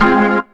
B3 FMIN 1.wav